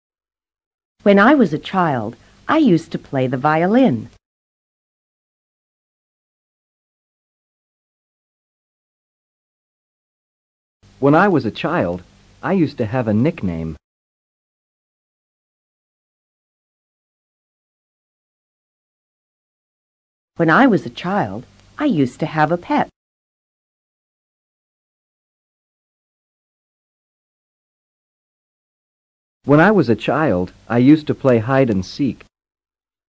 Pronunciación reducida de USED TO
Debido a que la d final de USED no se pronuncia con mucha fuerza, es posible que a veces creas estar escuchando USE TO. Este audio de práctica – repetido varias veces – te ayudará a percibir la sutil diferencia.